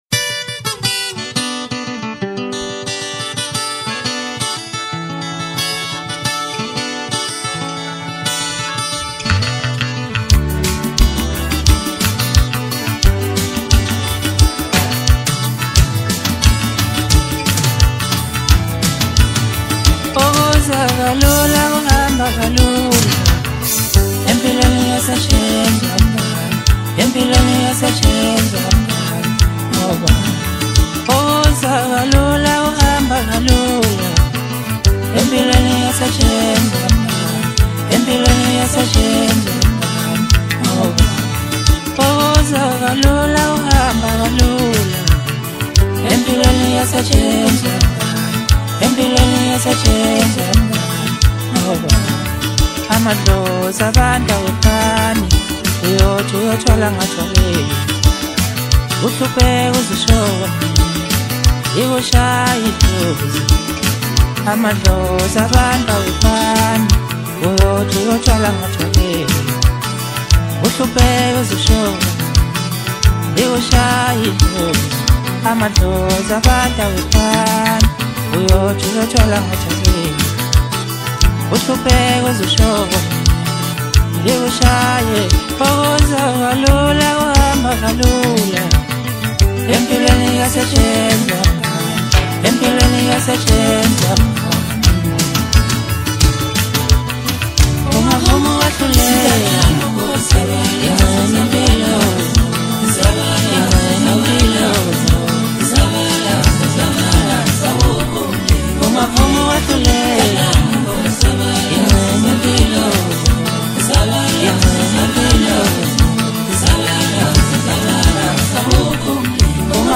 Home » DJ Mix » Hip Hop » Maskandi
South African singer-songsmith